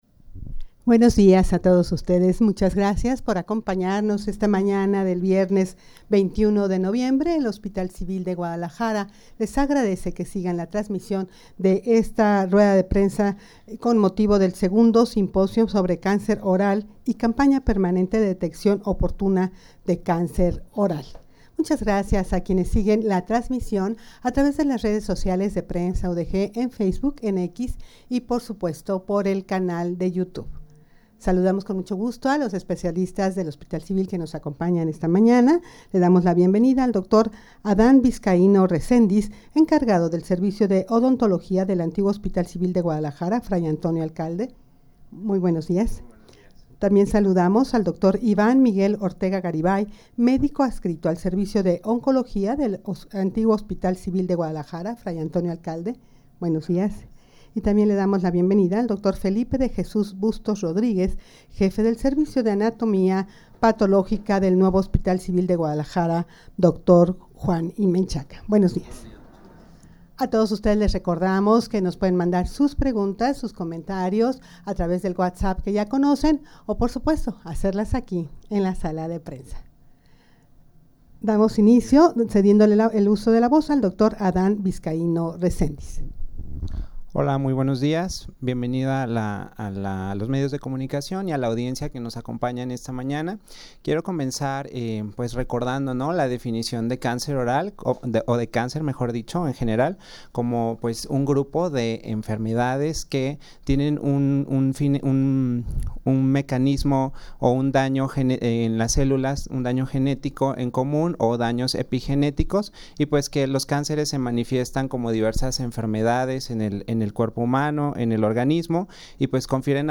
rueda-de-prensa-para-dar-a-conocer-los-pormenores-del-segundo-simposio-sobre-cancer-oral-.mp3